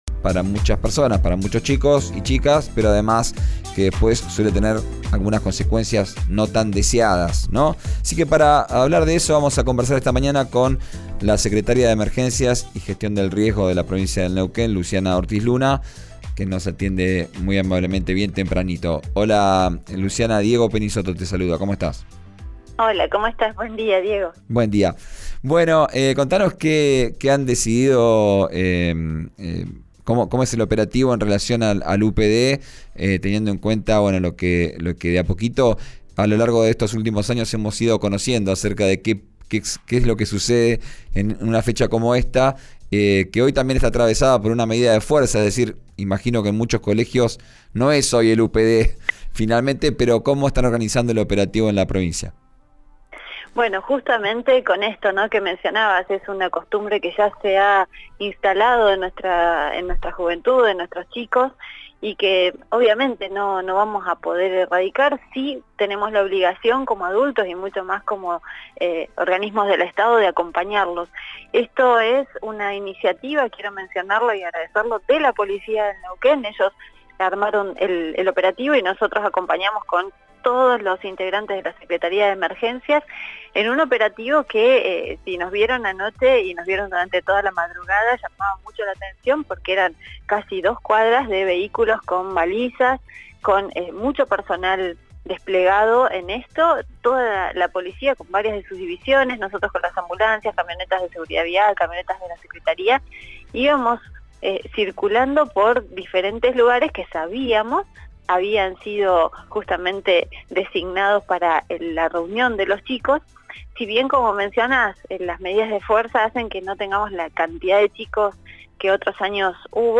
Por esto, organismos acompañaron a los alumnos y alertaron que, debido a los distintos días de inicio de clases, extenderán los operativos, contó la secretaria de Emergencias y Gestión del Riesgo, Luciana Ortiz Luna a RÍO NEGRO RADIO.